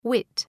Προφορά
{wıt}